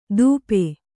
♪ dūpe